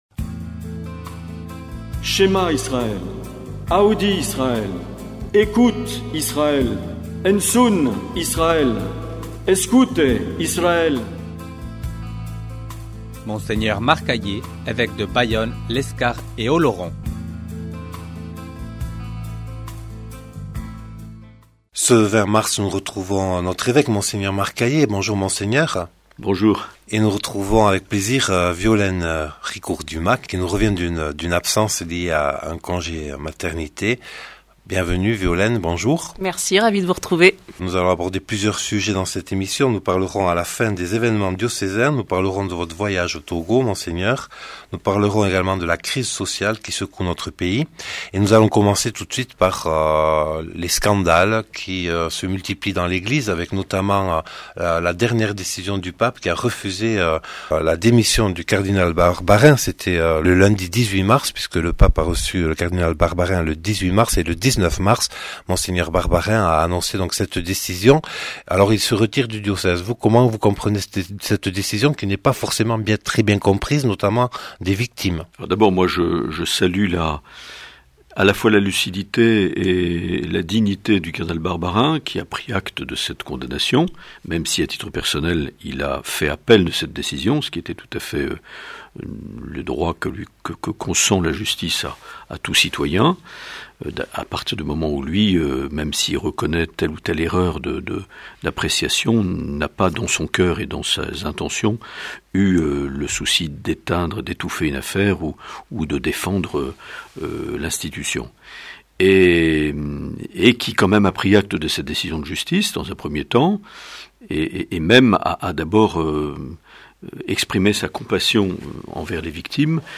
Les entretiens